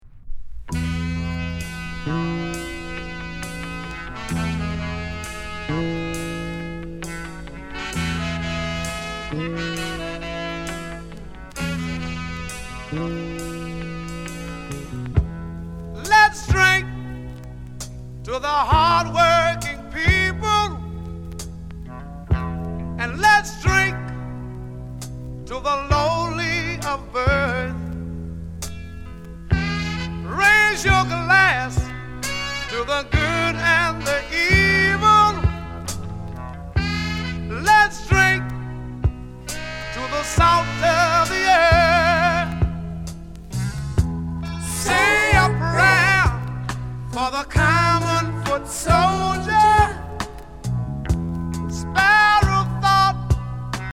JAMAICAN SOUL